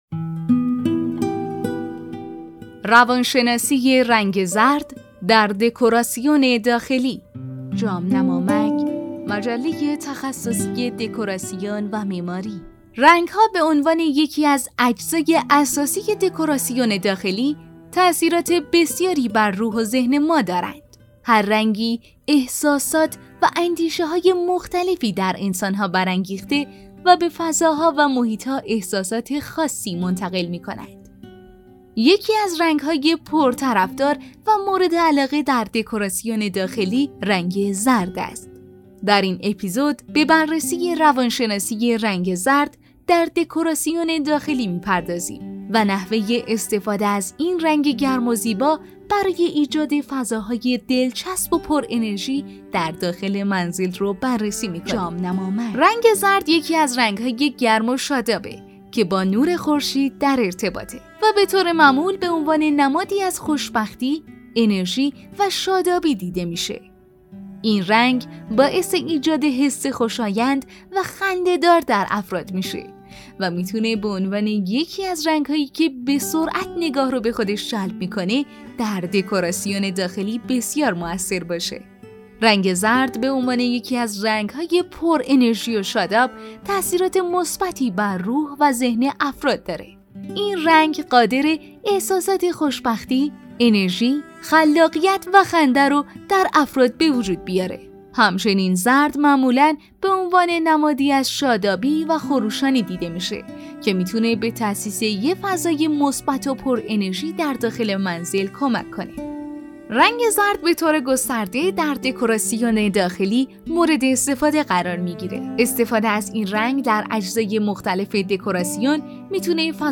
🎧نسخه صوتی مقاله روانشناسی رنگ زرد در دکوراسیون داخلی